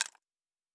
Weapon UI 07.wav